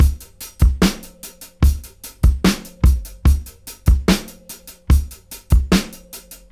73-DRY-01.wav